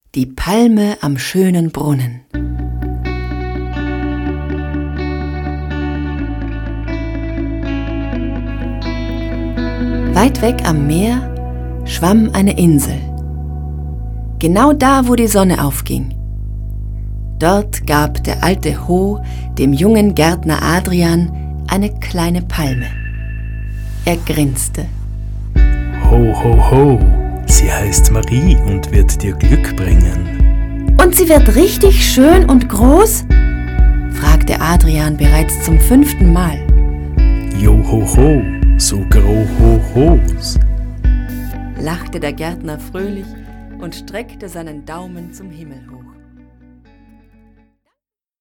Sprecherin Stimme: natürlich, freundlich, sinnlich Hörbuch & App für Kinder
Sprechprobe: Sonstiges (Muttersprache):
Hörbuch für Kinder - ASAGAN.mp3